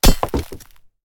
axe-mining-ore-3.ogg